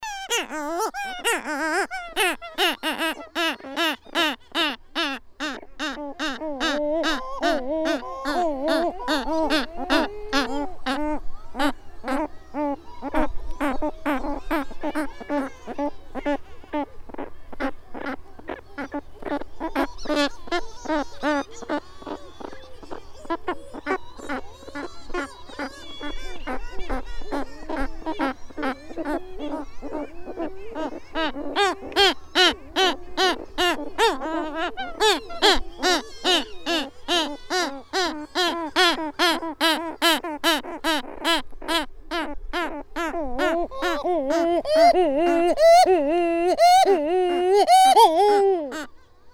Parela cabeza negra.wav